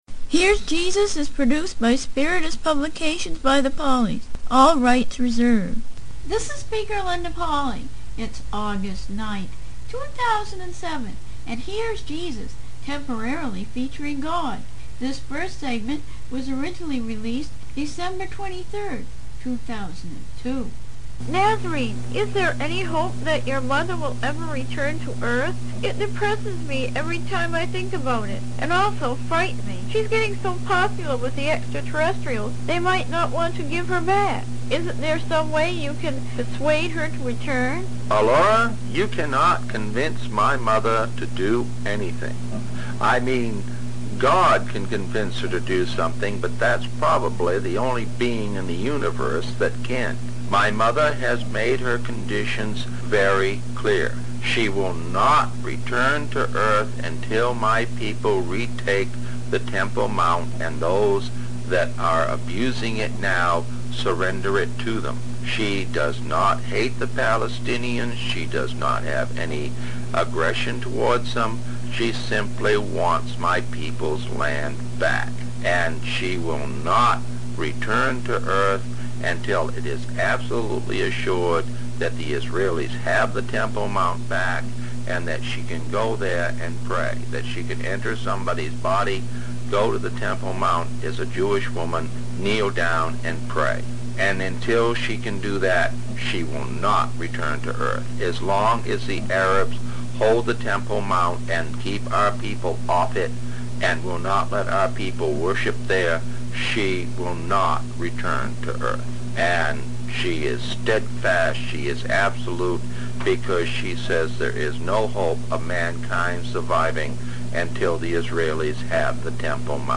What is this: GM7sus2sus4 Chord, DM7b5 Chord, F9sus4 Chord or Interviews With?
Interviews With